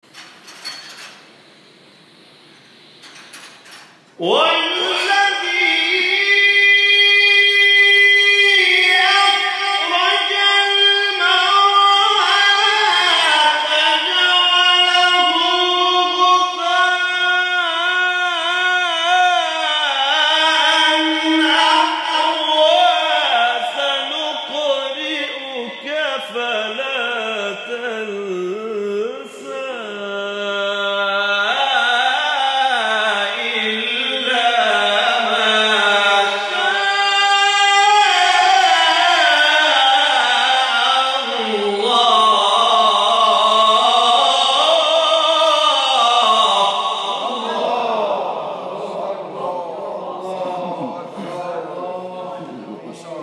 شبکه اجتماعی: فرازهای صوتی از تلاوت قاریان برجسته و ممتاز کشور را که به‌تازگی در شبکه‌های اجتماعی منتشر شده است، می‌شنوید.